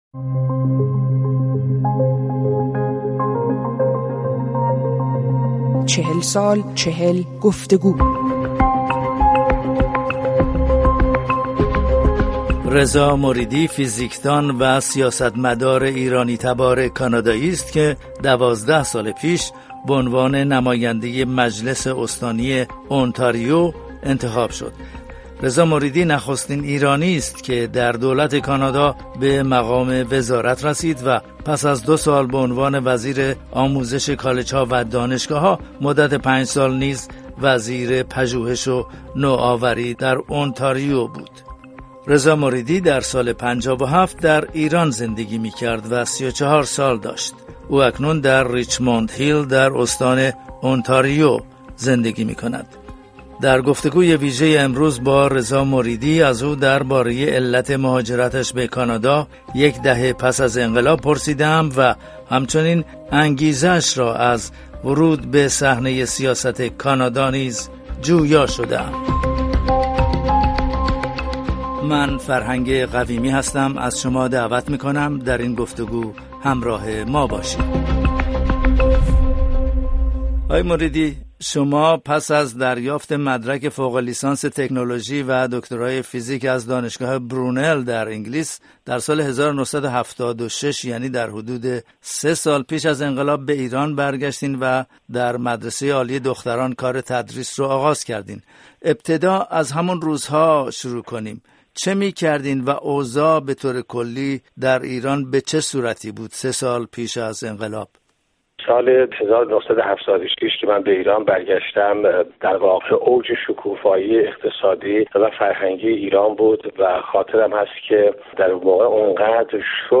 گفت‌وگو با رضا مریدی